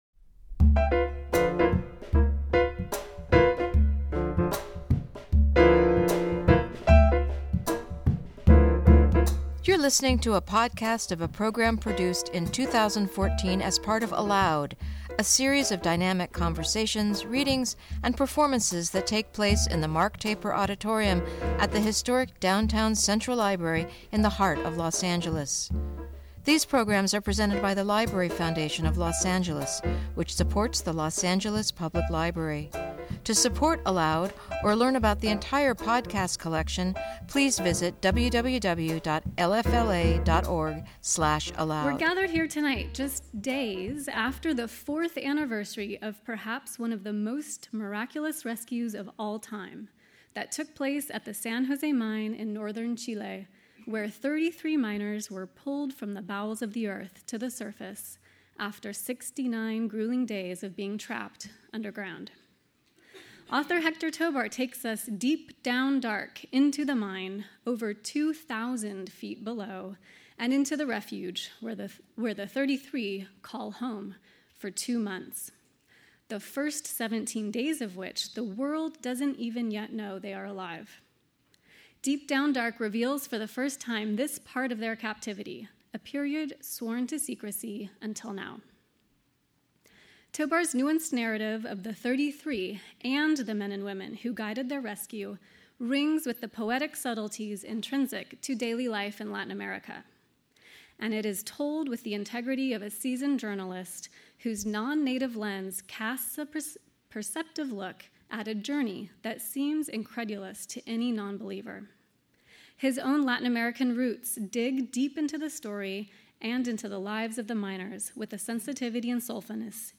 Héctor Tobar In conversation with author